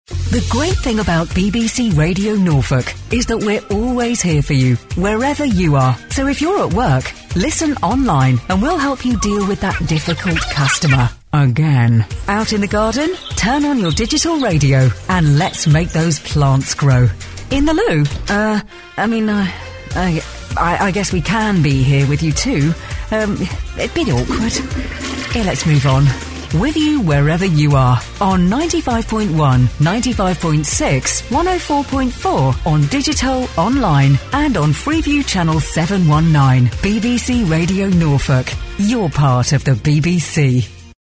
English (British)
Promos